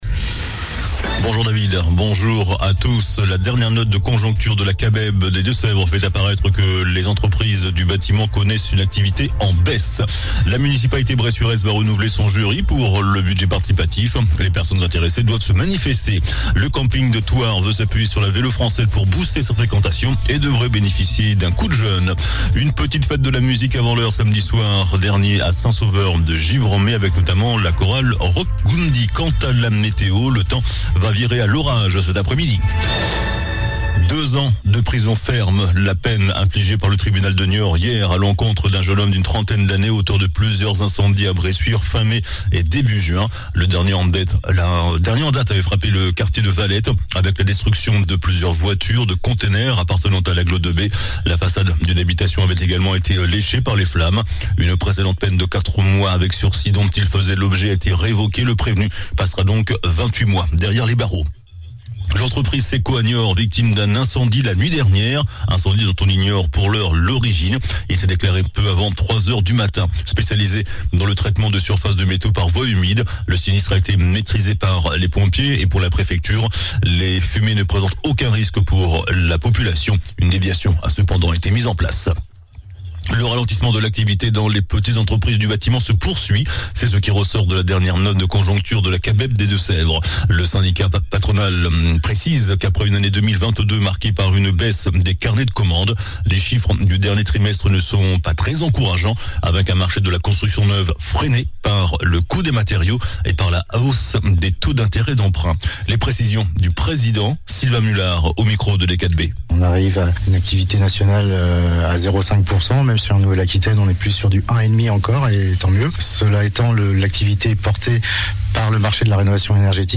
JOURNAL DU MARDI 13 JUIN ( MIDI )